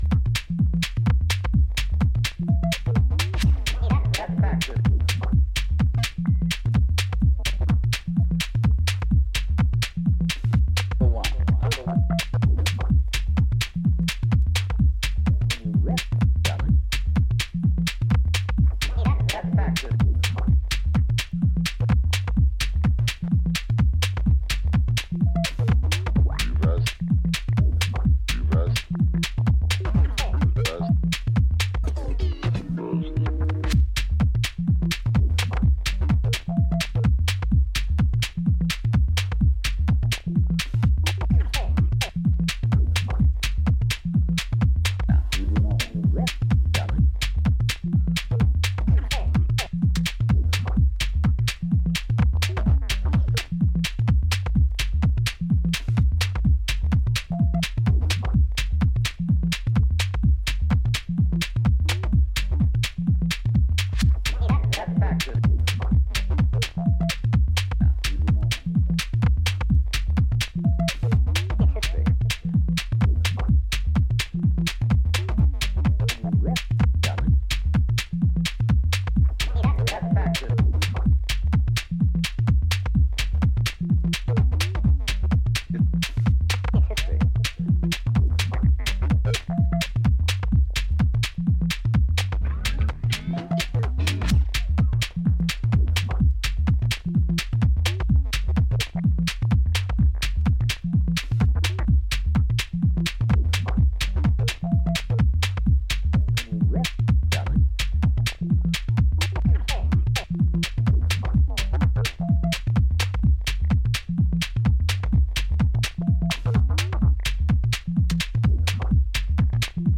いずれの楽曲も、巧妙なインテリジェンスを感じさせるテクスチャーが構築されたアブストラクトなミニマル・ハウスといった趣。